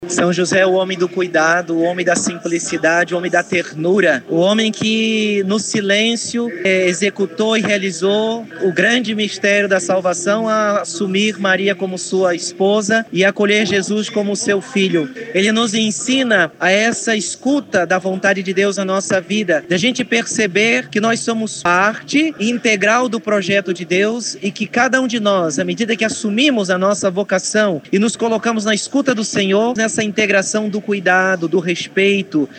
A Santa Missa foi campal, presidida pelo bispo auxiliar de Manaus, Dom Samuel Lima, e concelebrada por alguns padres do Setor Centro Histórico e padres Salesianos.